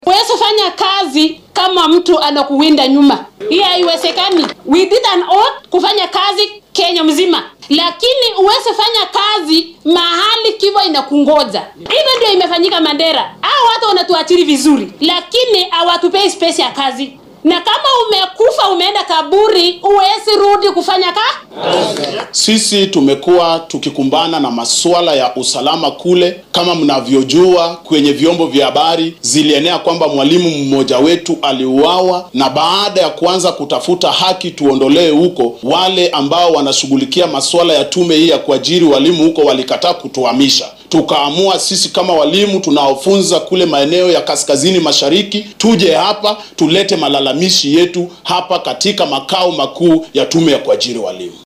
Qaar ka mid ah barayaasha dibadbaxa dhigay ayaa warbaahinta la hadlay